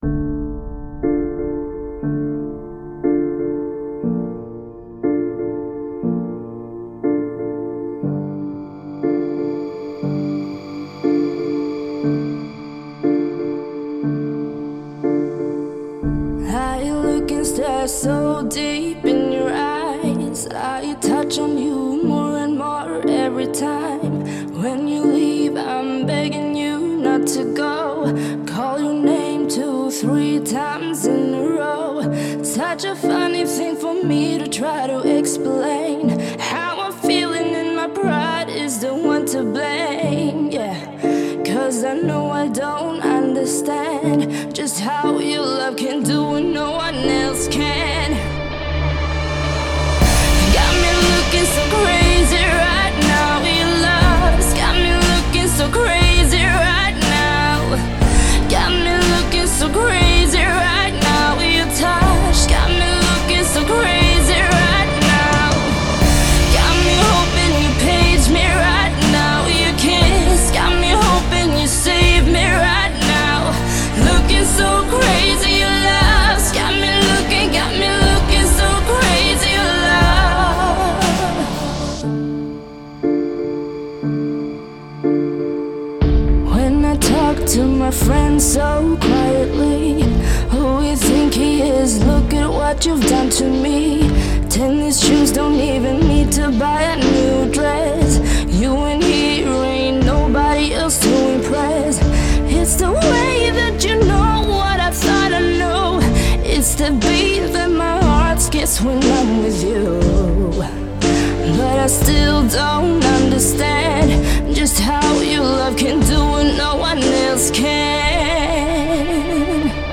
Live
Cover